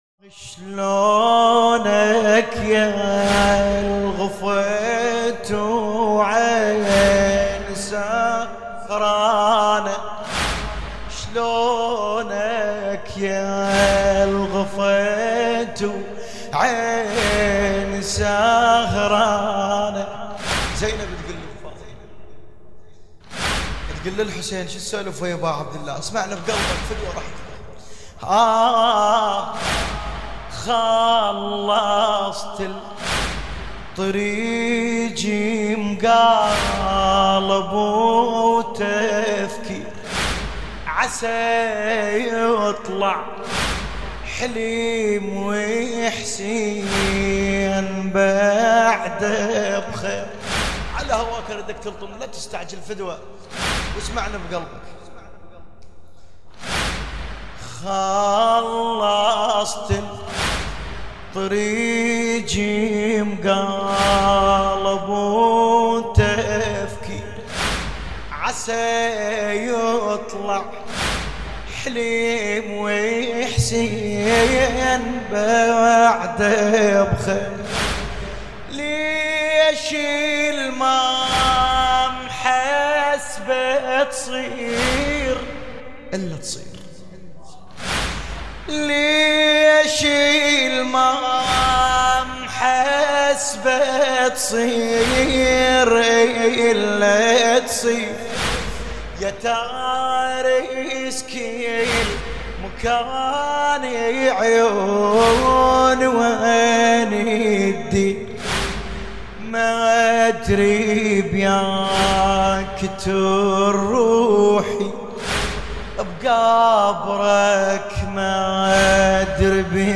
لطميات